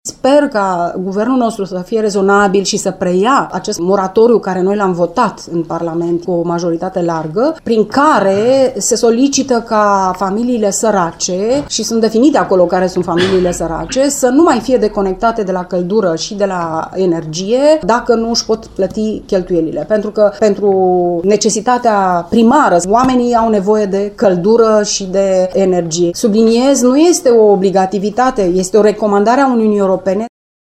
Un document in acest sens a fost votat in Parlamentul European şi reprezintă o recomandare pentru ţările membre, explică europarlamentarul de Timis, Maria Grapini: maria grapini saracie euro ECON